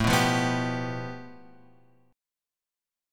A7sus2#5 chord {5 2 3 4 x 3} chord